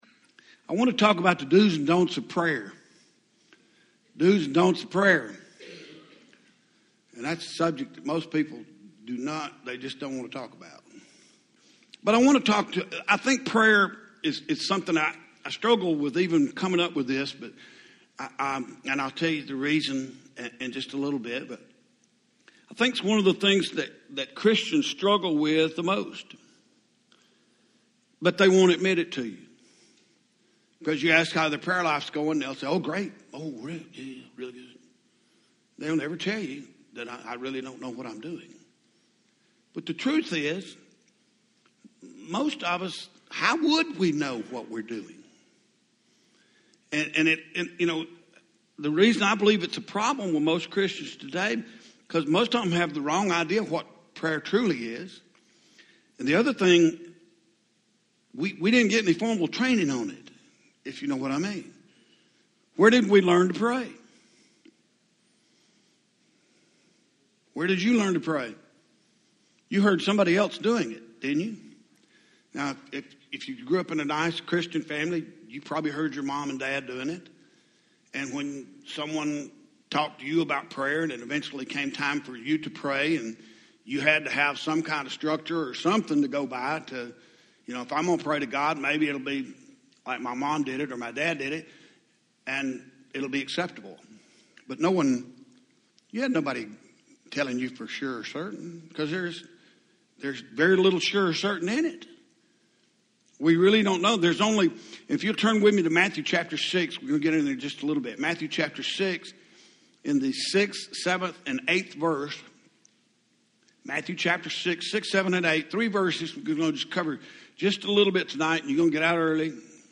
Home › Sermons › The Do’s And Don’ts Of Prayer